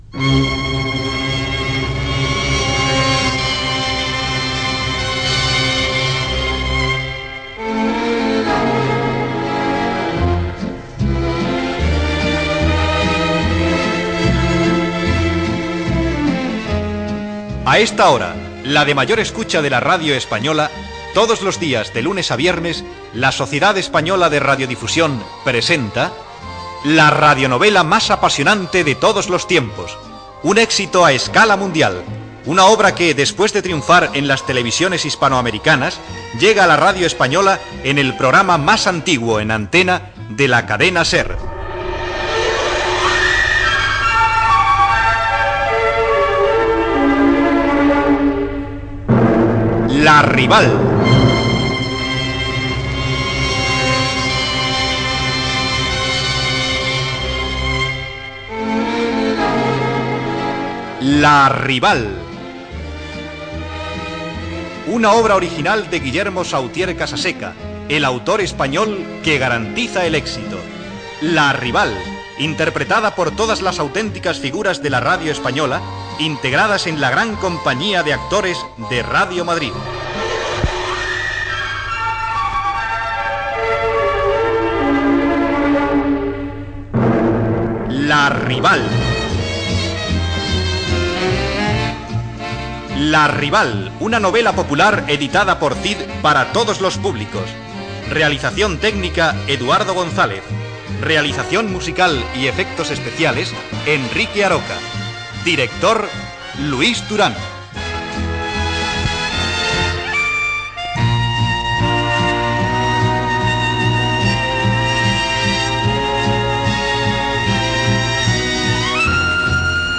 Careta del serial amb els noms de l'equip.
Ficció